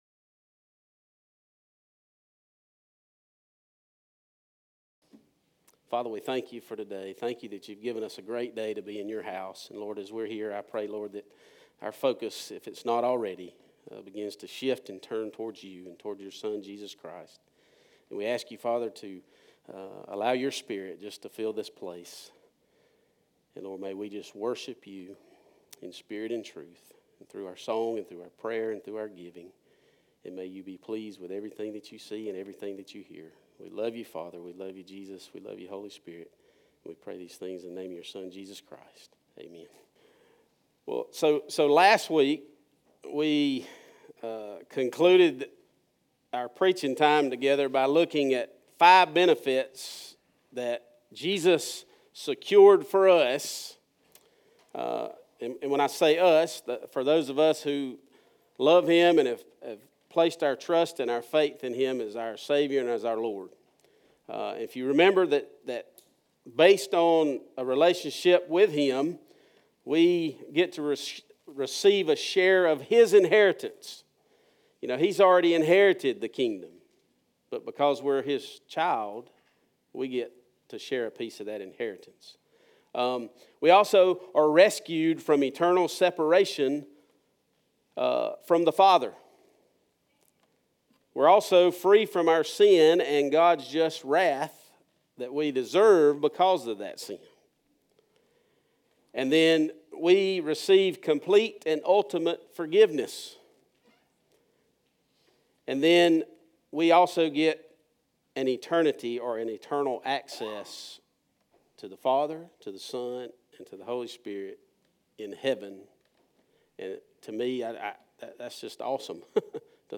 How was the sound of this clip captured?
Occasion - Sunday Worship